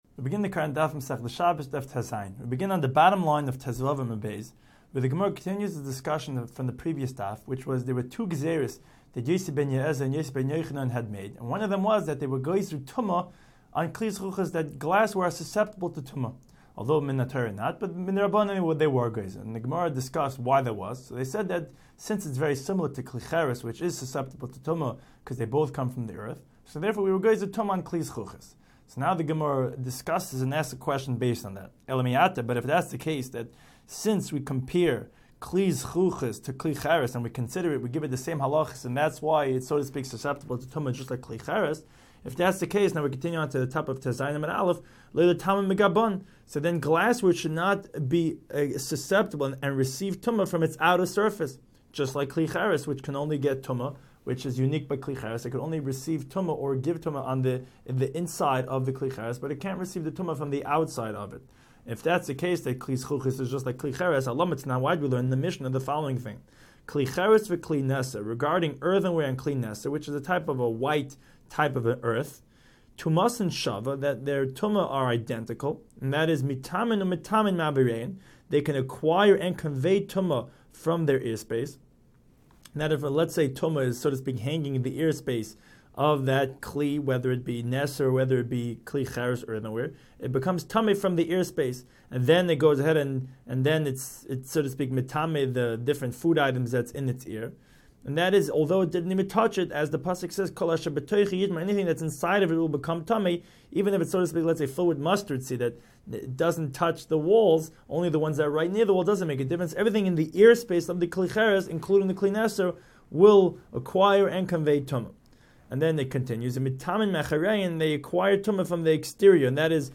Daf Hachaim Shiur for Shabbos 16